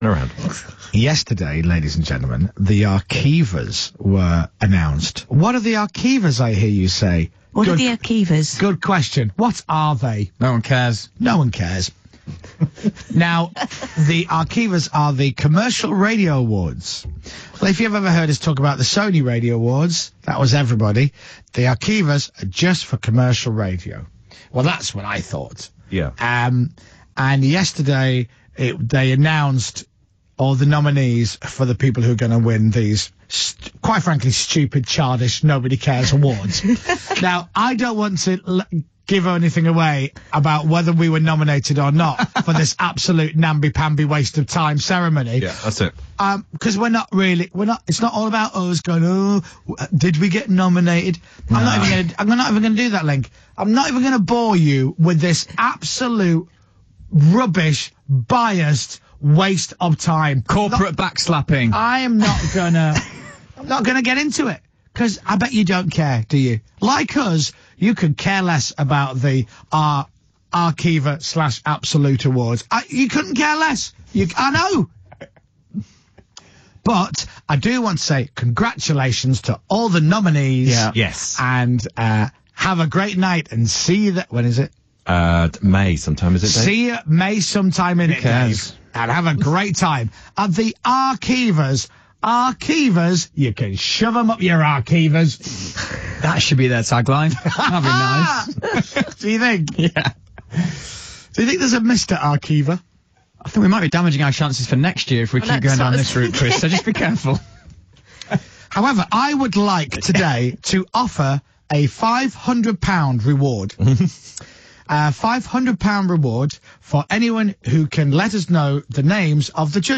We gather from this rant on-air the morning after the nominations are announced, that Moyles isn't too happy about not getting shortlisted